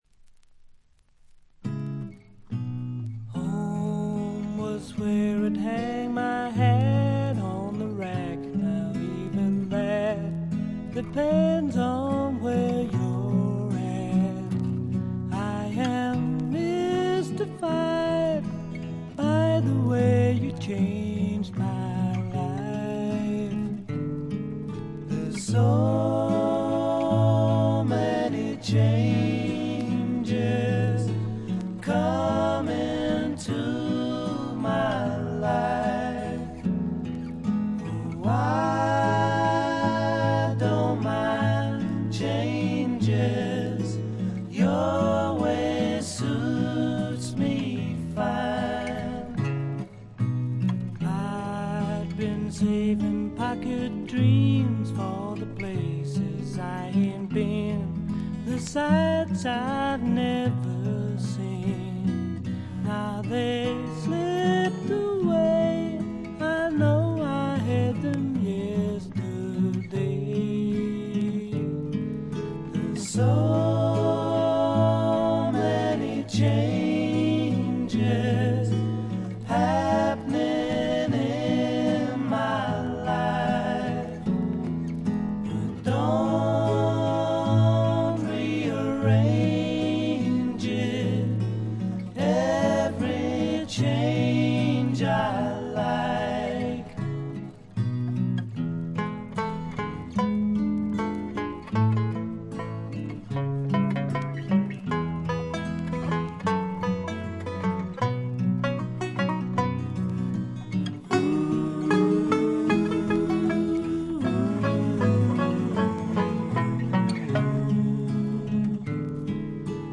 渋い英国産スワンプ、理想的な「イギリスのアメリカ」！